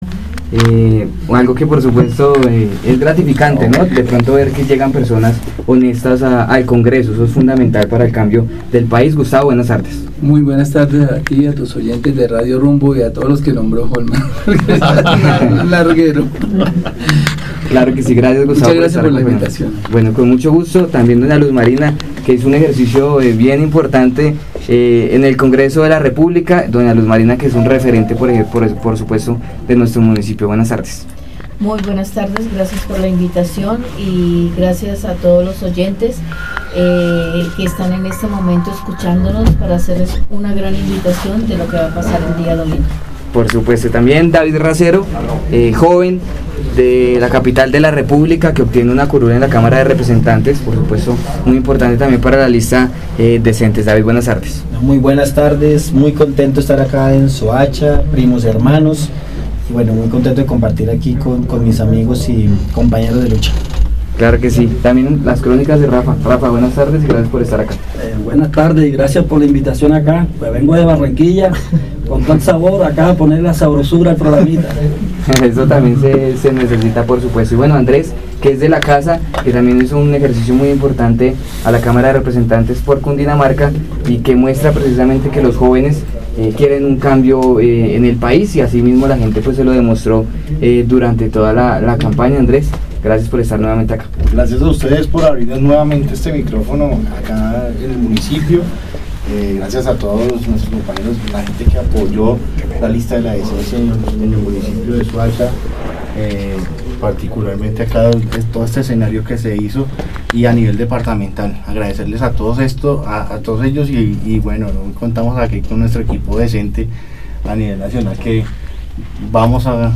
En la rueda de prensa realizada en Soacha y ofrecida por voceros de la lista Decentes